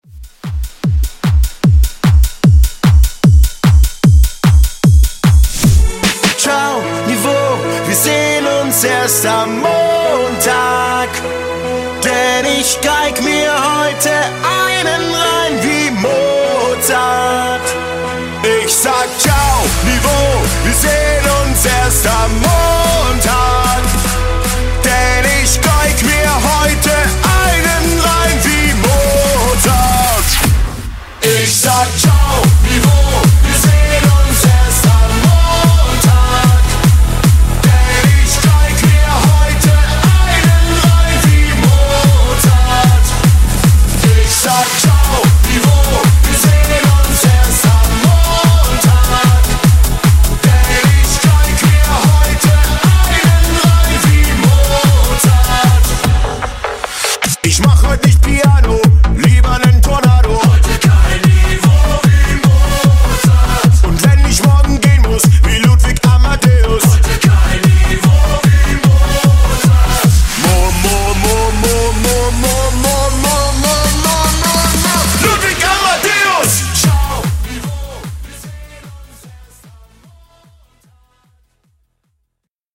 Genre: GERMAN MUSIC
Clean BPM: 151 Time